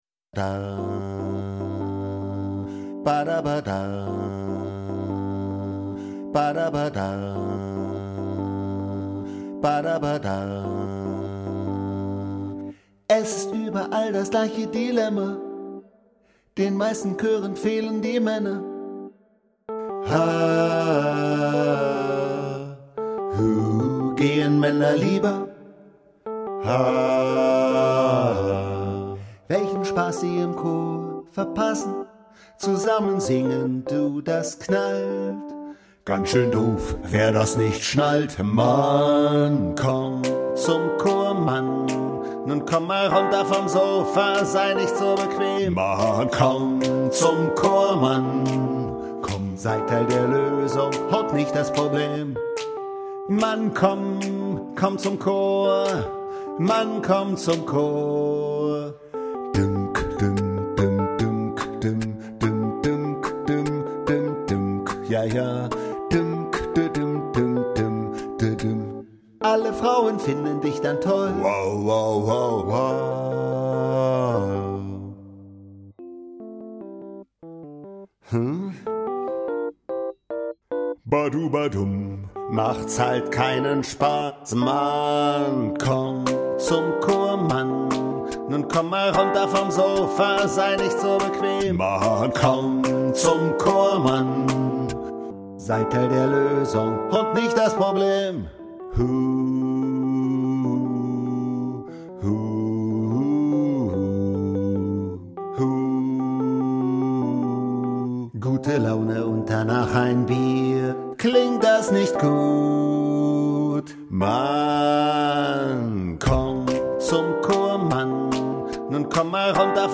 • Besetzung: 4-stimmig, a cappella
Bassstimme